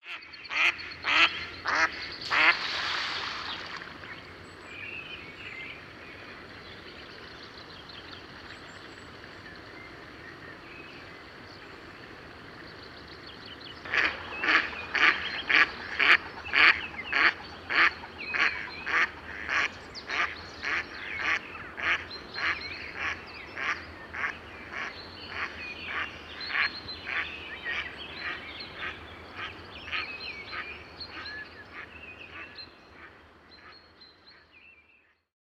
Mallard – River Island Conservancy
Audio Call
It is a noisy species that inhabits most wetlands, including parks, ponds, and rivers.